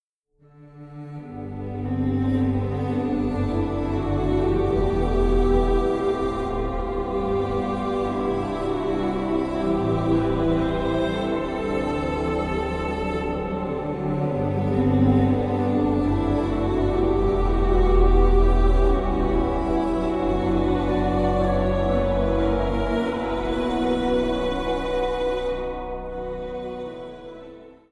kerkorgels
dit orgel heeft allerlei orkeststemmen